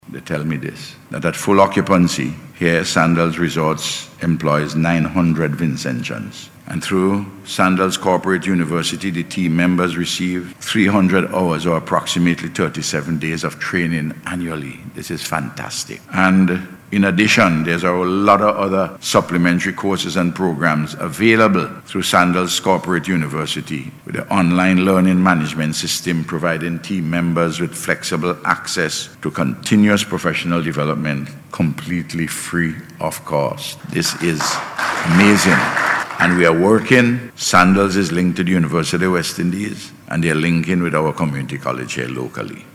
He made this statement during yesterday’s signing ceremony for the construction of a Beaches Resort at Mt. Wynne/Peter’s Hope.